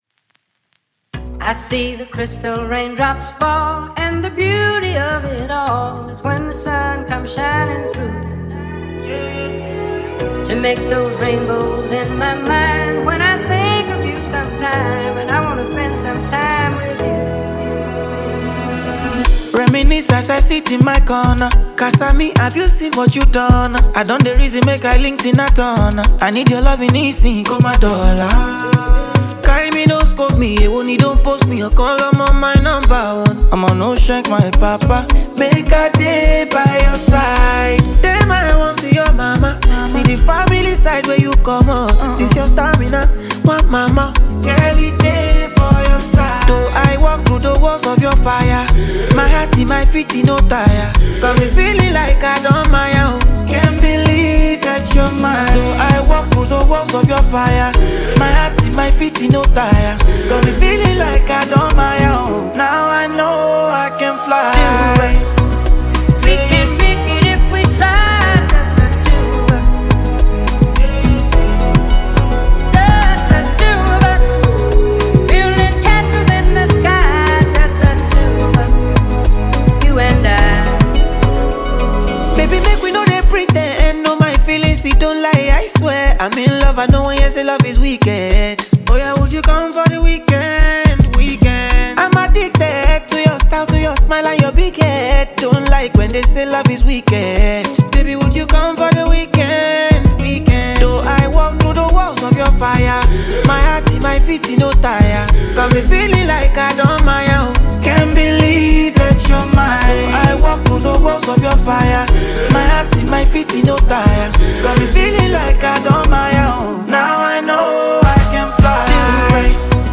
New banger from Nigerian Afrobeat star